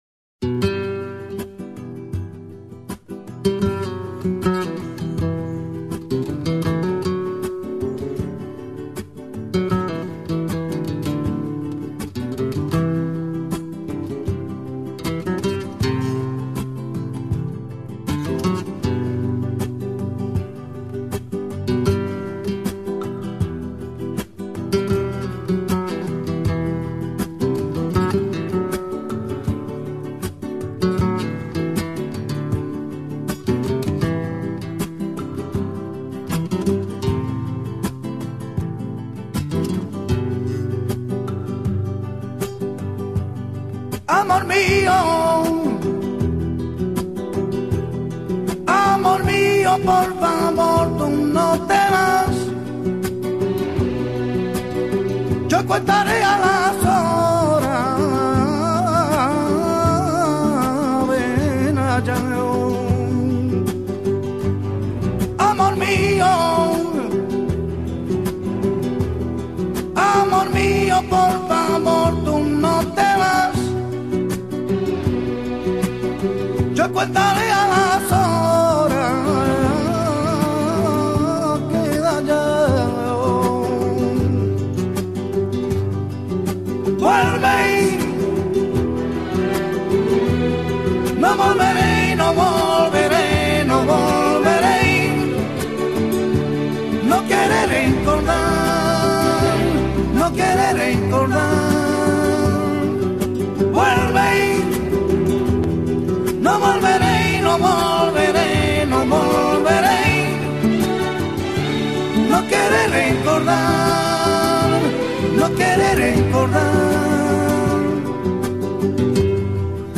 صدای زن در کار نیست